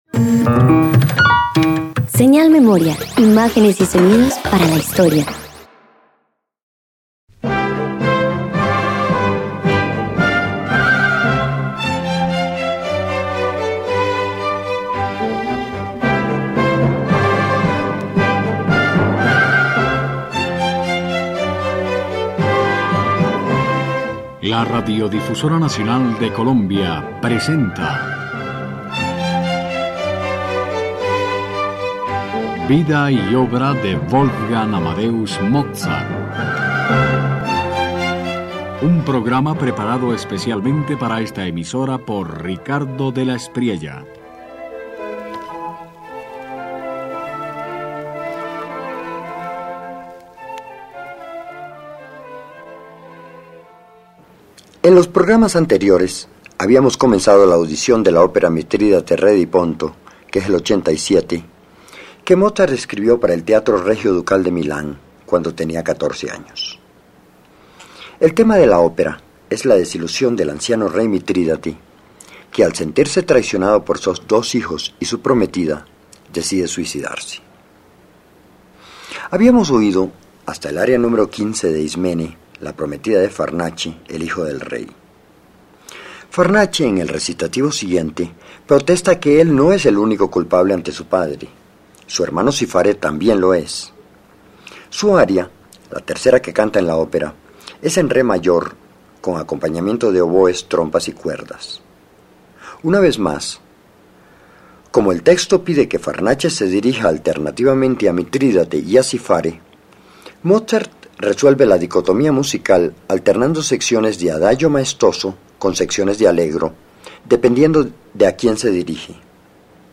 En su última aria, “Vado incontro”, Mitrídate acepta la muerte como destino. La melodía avanza con paso firme, casi ritual, hasta volverse himno de redención.